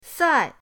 sai4.mp3